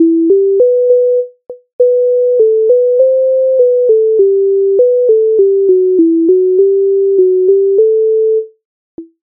MIDI файл завантажено в тональності e-moll
Ой піду я до млина Українська народна пісня зі збірки Михайловської
Ukrainska_narodna_pisnia_Oj_pidu_ia_do_mlyna.mp3